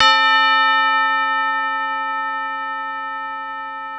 Dre-Bell 2.wav